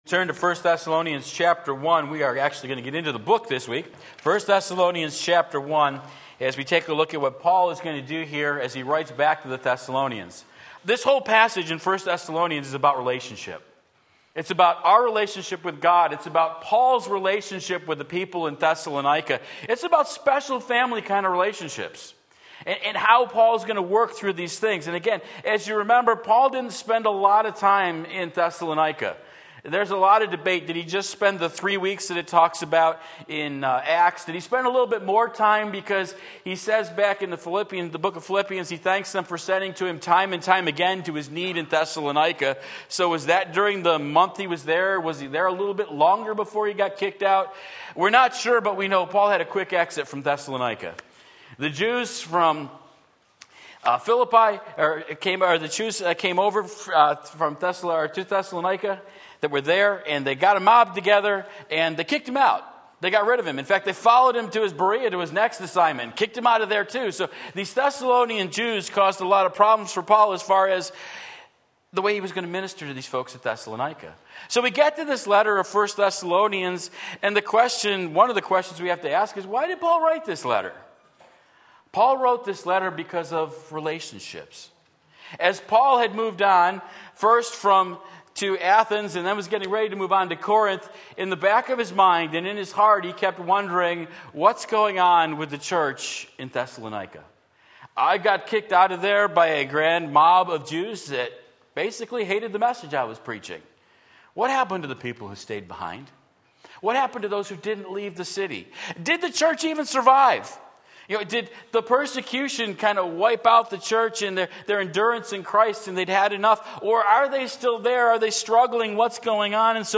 Sermon Link
Sunday Morning Service